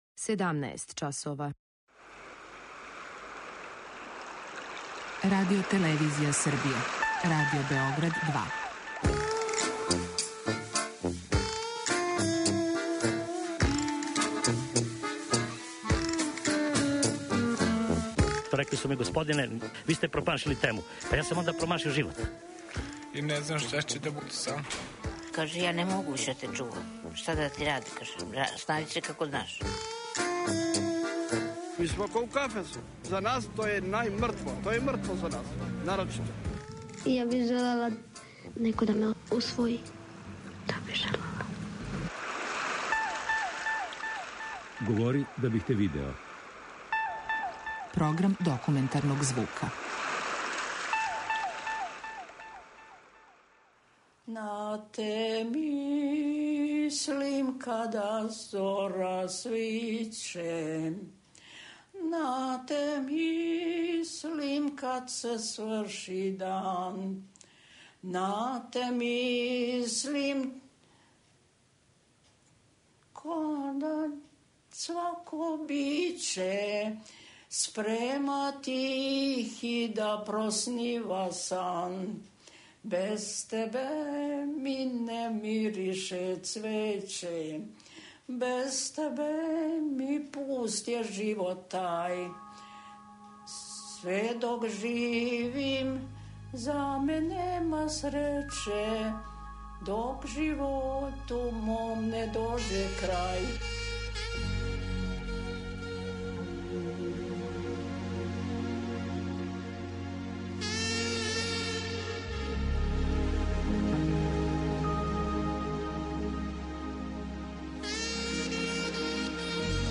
Документарни програм
Баке и деке, у осмој и деветој деценији живота, говоре о сећањима на своје прве пољупце и никад заборављене прве љубави.